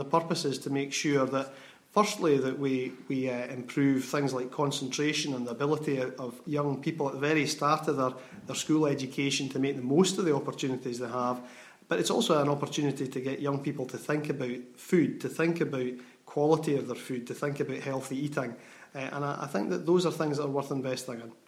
Learning Minister Dr Alasdair Allan says it's a big investment in Scotland's future.